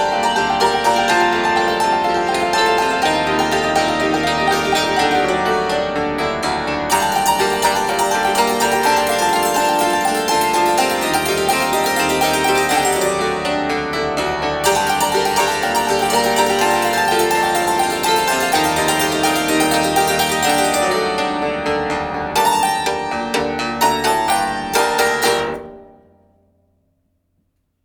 Hackbrettquartett